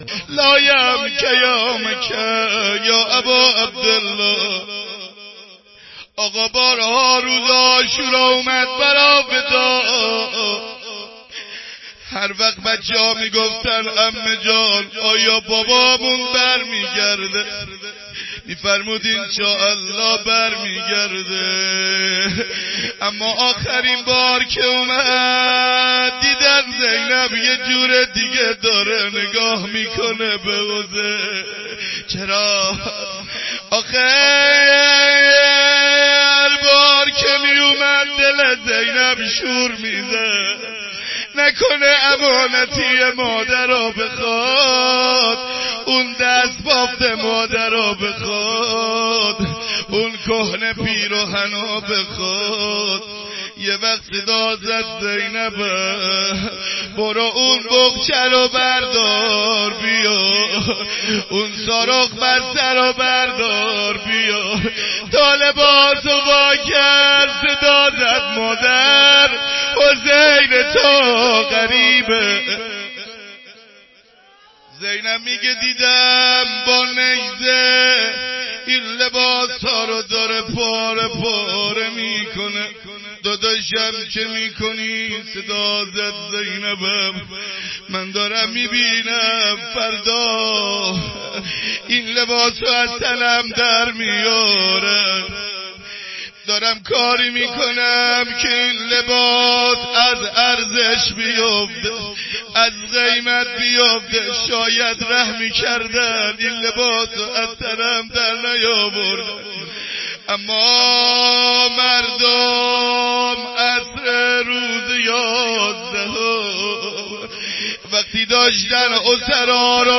روضه خانم حضرت زینب سلام الله علیه